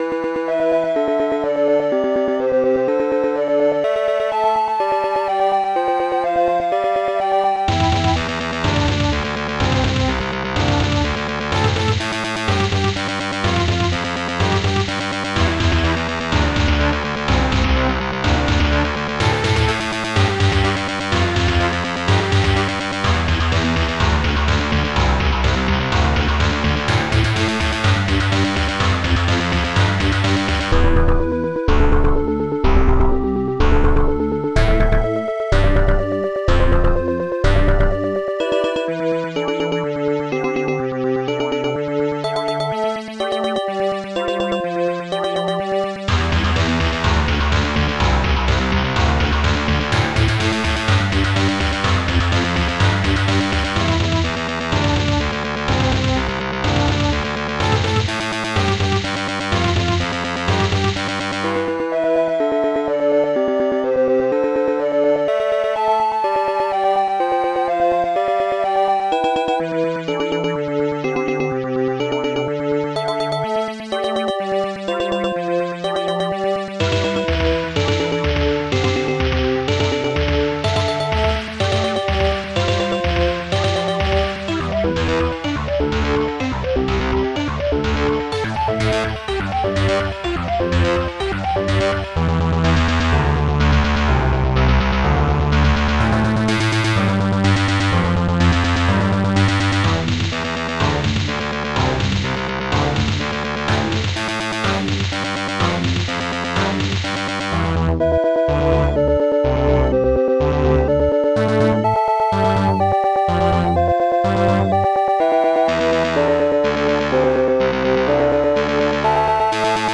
st-01:popsnare2
st-02:hammerbass
st-02:elecguitar
st-01:synbrass
st-01:panflute
st-01:celeste